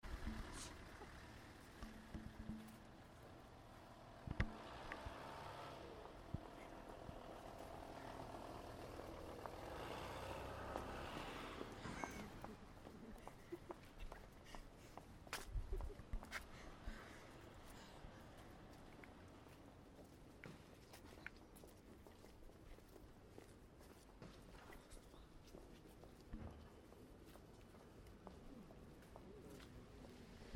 Rue pietonne Limoges
bruit de trafic, des ricanements, bruit de tam-tam.